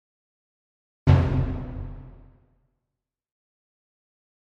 Drum Deep Symphonic - Orchestra Drum Single Hit